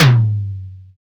Index of /90_sSampleCDs/Roland L-CD701/DRM_Analog Drums/TOM_Analog Toms
TOM DDR TOM3.wav